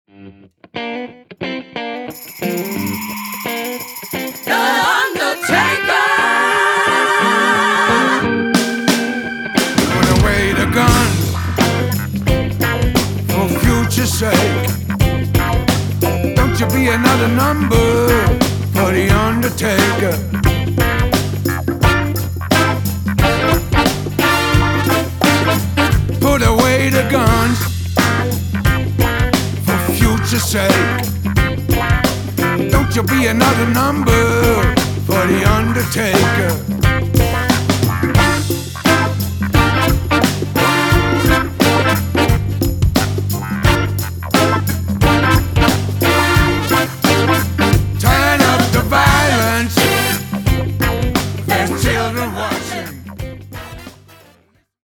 PIANO, HAMMOND, WURLITZER
RECORDED AT MALACO RECORDING STUDIOS, JACKSON, MISSISSIPPI
RECORDED AT FAME RECORDING STUDIOS, MUSCLE SHOALS, ALABAMA